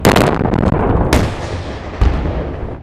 explosion 2